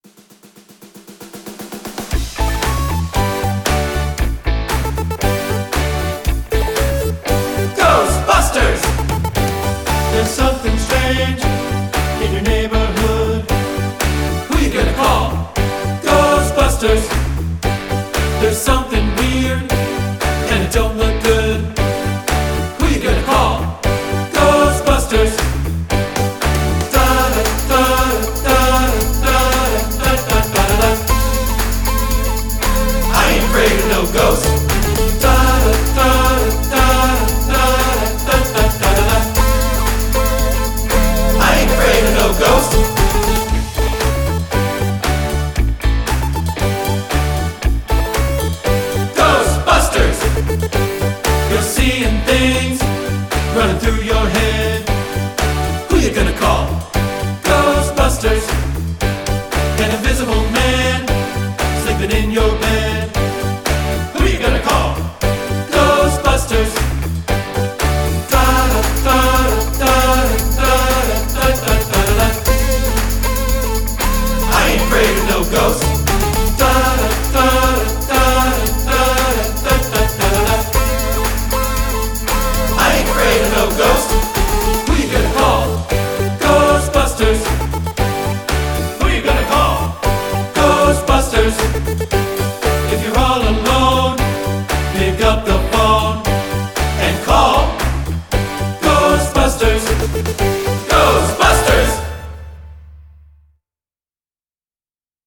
Voicing: TB